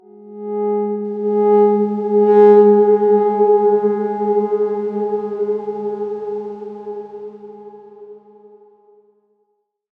X_Darkswarm-G#3-pp.wav